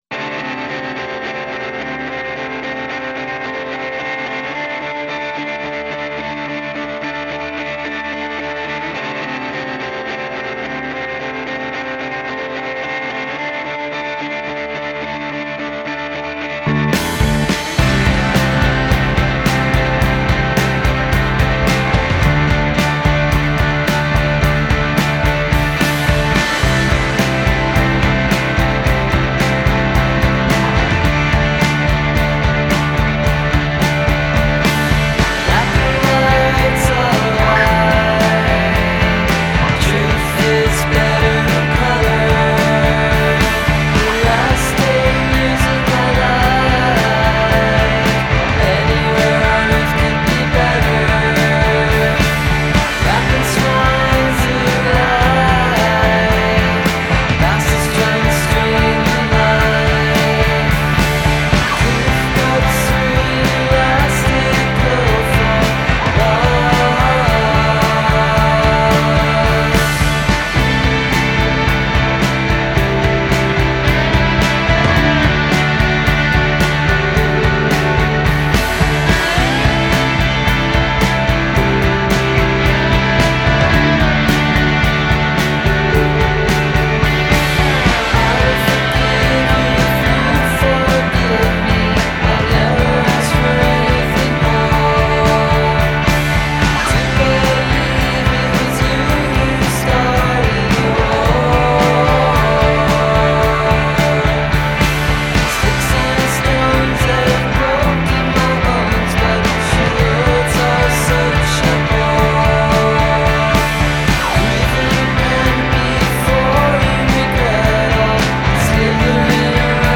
Harmonies, wailing whining guitars and eerie vocals.
guitars, vocals, loops
bass, keys, vocals
drums, vocals